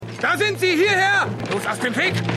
DrQuinn_3x08_Mutter2_Mutter1.mp3 - beides die gleiche Stimme